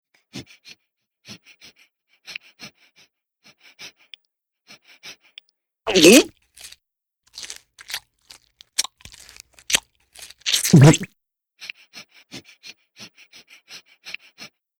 beg_eat_swallow.mp3